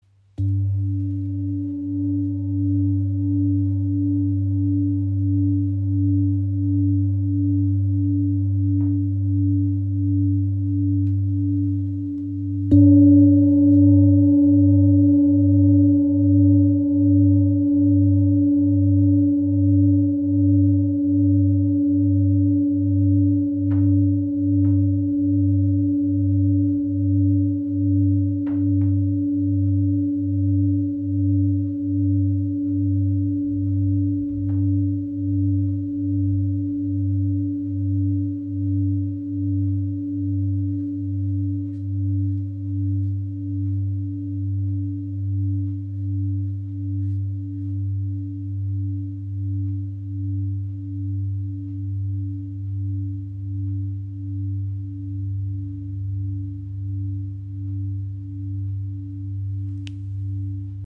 Klangschale mit Blume des Lebens | ø 26 cm | Ton ~ G2 | Mondton (95,96 Hz)
• Icon Inklusive passendem rotem Filzschlägel. Gewicht 1857g.
• Icon Grundton G2 bei 436Hz – nahe am gefühlvollen Mondton.
Mit ihrem sanften Grundton von ca. 97,2 Hz öffnet sie Deine Intuition, fördert innere Ruhe, Geborgenheit und Klarheit.